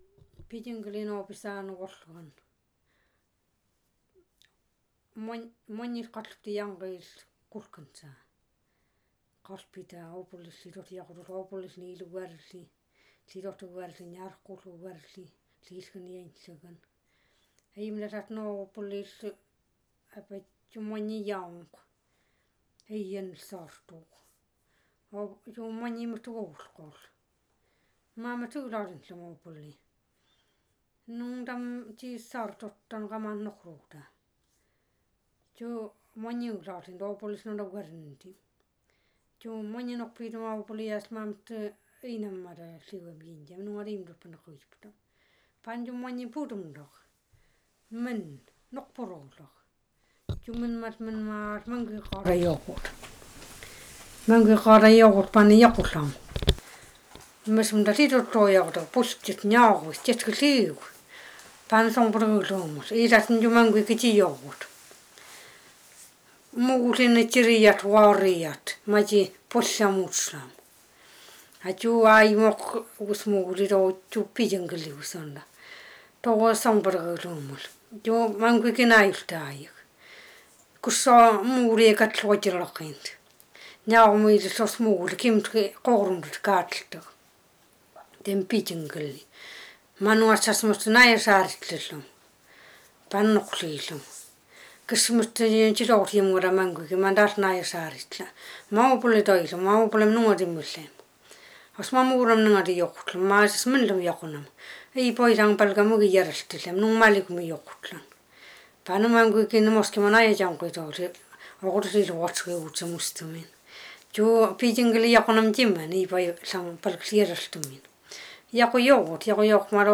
yugan khanty (YK)
Tales (tal)